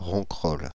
Ronquerolles (French pronunciation: [ʁɔ̃kʁɔl]
Fr-Paris--Ronquerolles.ogg.mp3